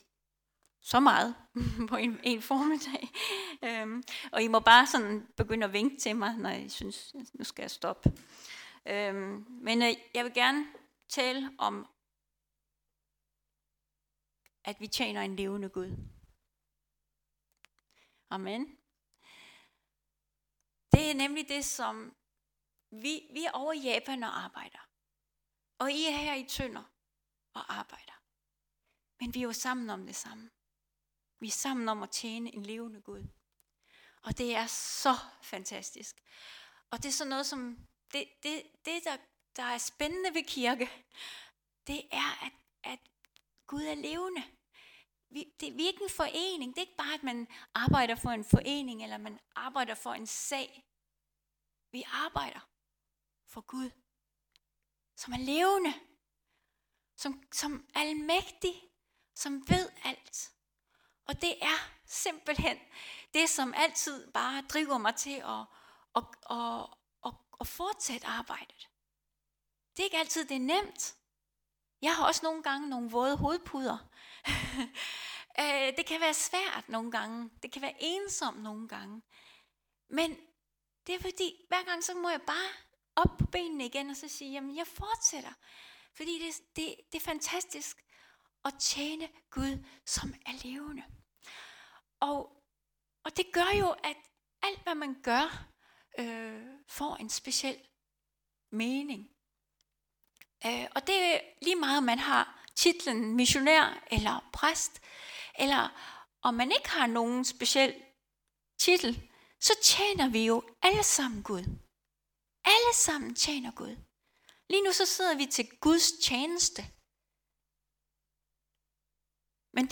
Series: Prædikener fra Tønder Frikirke Service Type: Gudstjeneste